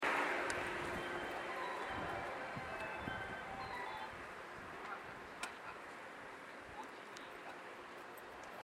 発車メロディー途中切りです。